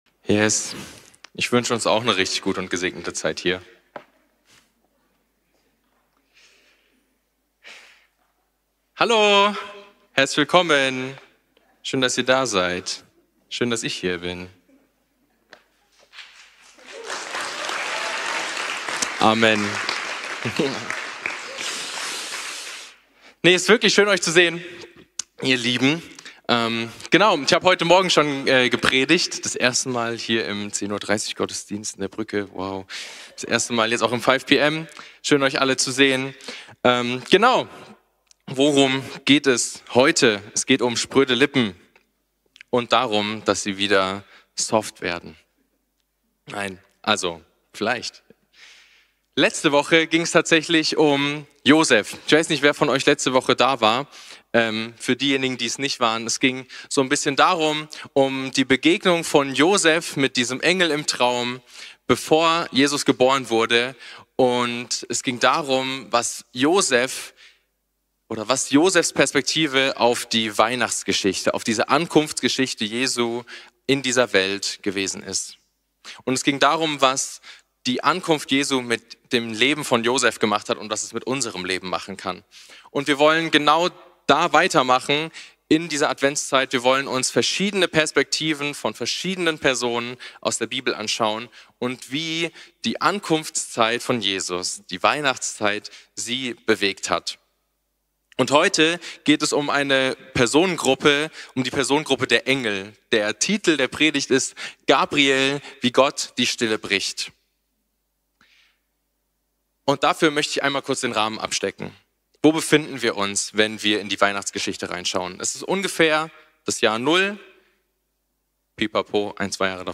Advent Typ: Predigt Gott kommt in die Welt